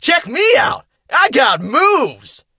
fang_kill_vo_04.ogg